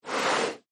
Звуки снежного барса
Шум снега под лапами белого барса